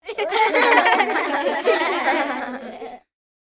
Kids Chuckling
yt_gN1tERd_WYU_kids_chuckling.mp3